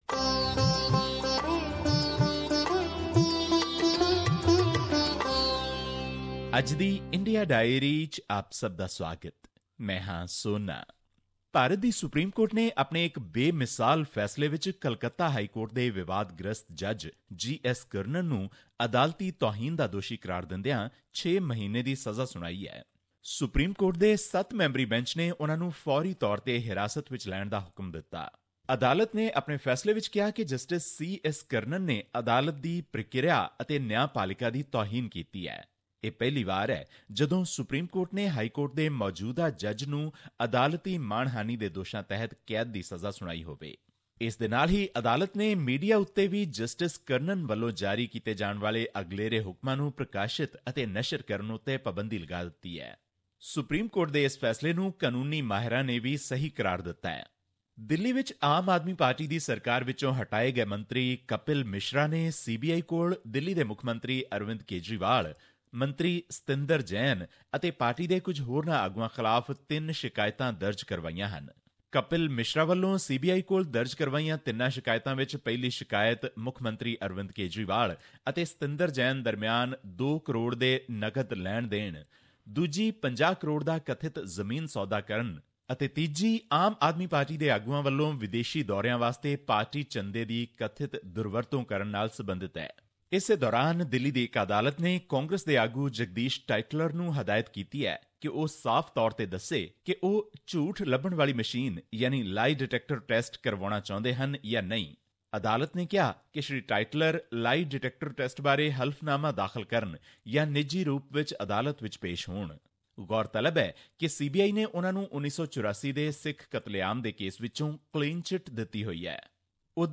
His report was presented on SBS Punjabi program on Thursday, May 11, 2017, which touched upon issues of Punjabi and national significance in India. Here's the podcast in case you missed hearing it on the radio.